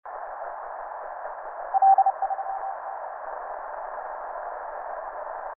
DA0HQ      on 10m CW - IARU HF championship 2007 - bursts almost every minute - QRO and a good ANT helps a lot